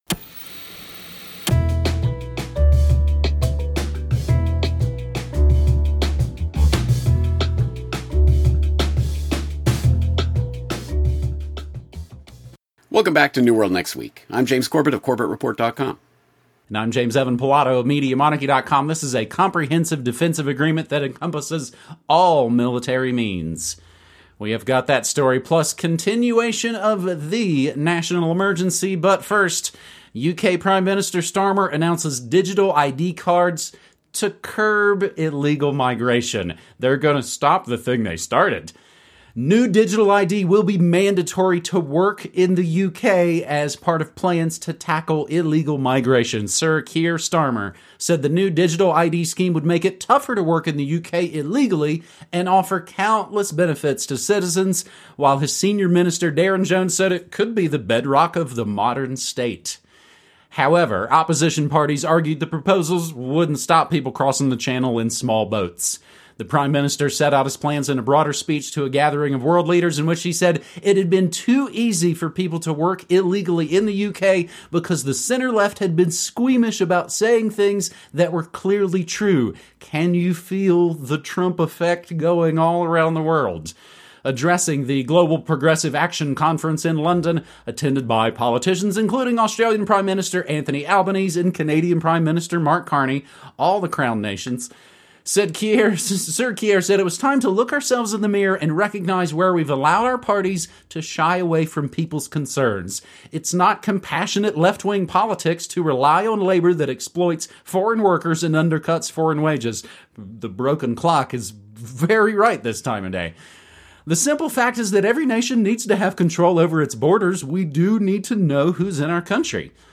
Issues covered include 9/11 and false flag terror, the Big Brother police state, the global warming hoax and how central banks control the political process. Guests include politicians, scientists, activists and newsmakers from around the world.